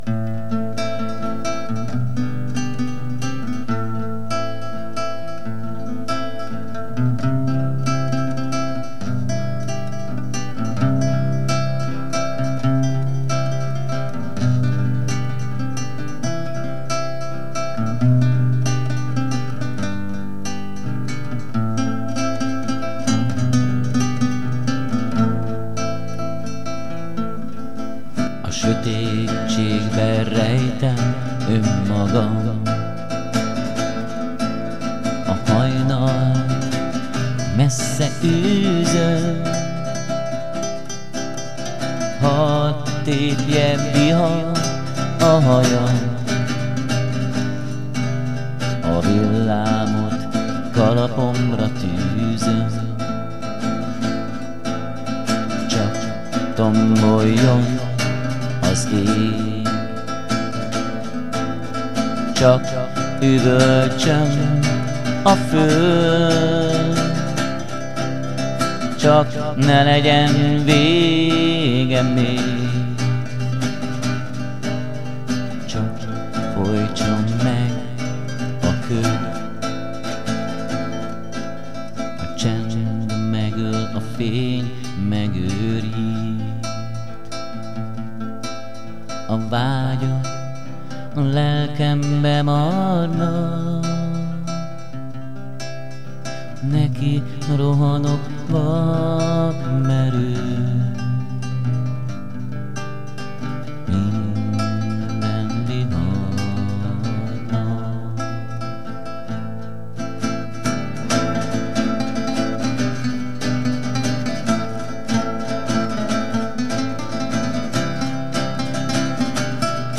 ének, furulya
gitár
hegedű